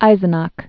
(īzən-äk, -ä)